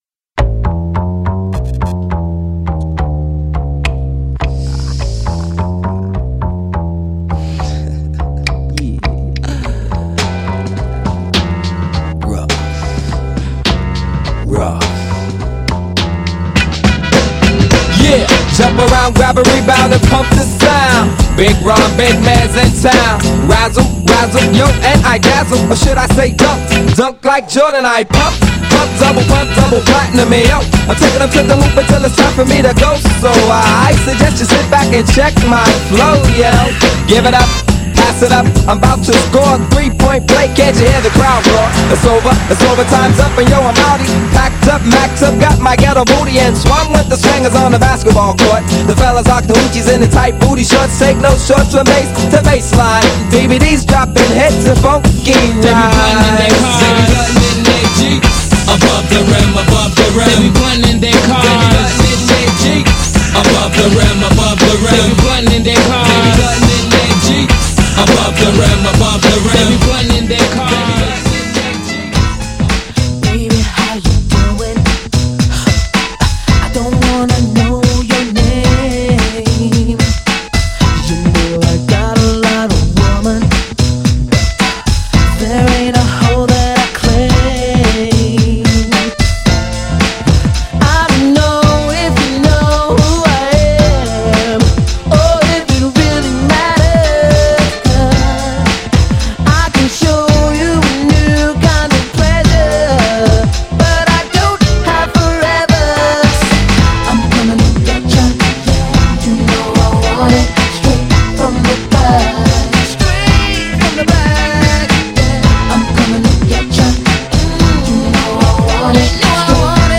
カップリングはアッパーなNEW JACK3曲のメドレーです!!
GENRE R&B
BPM 61〜65BPM